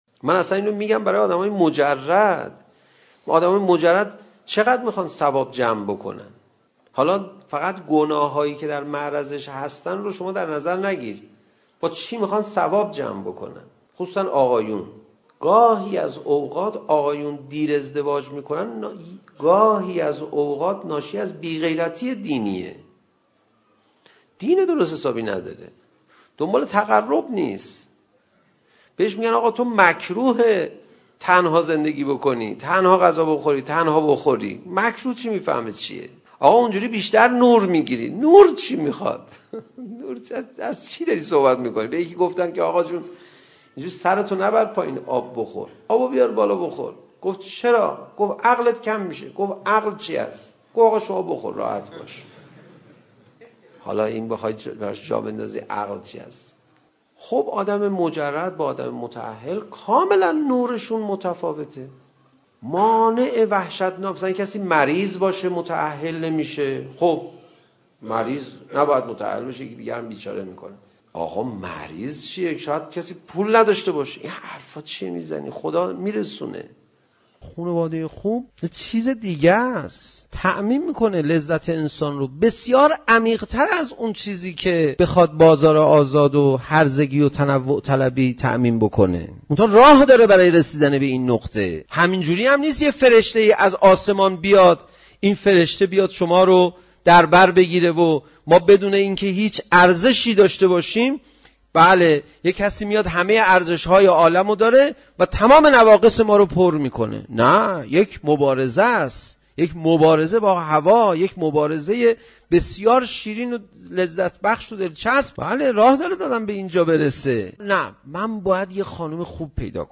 حاصل خلاصه برداری صوتی و موضوعی سخنرانی حجت الاسلام پناهیان با عنوان خانواده خوب